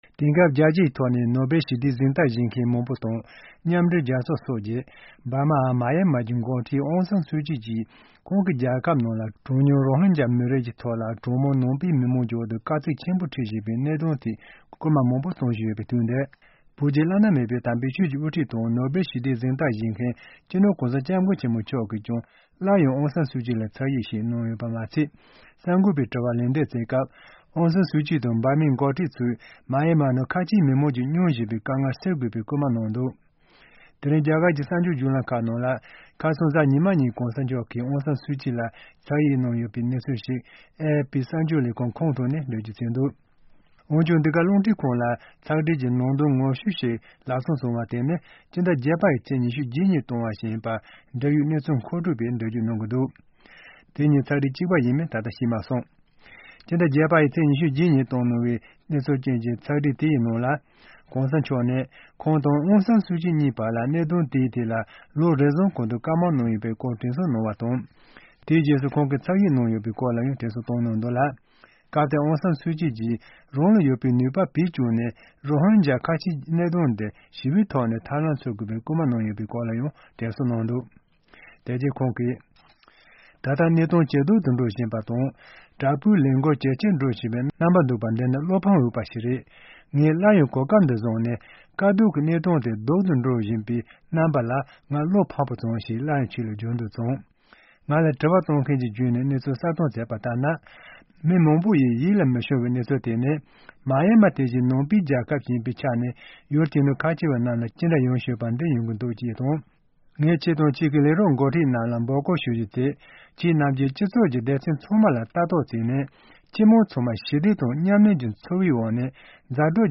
ཕྱོགས་བསྒྲིགས་དང་སྙན་སྒྲོན་ཞུས་གནང་གི་རེད།